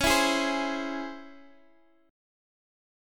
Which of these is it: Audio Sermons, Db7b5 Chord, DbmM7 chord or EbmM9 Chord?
DbmM7 chord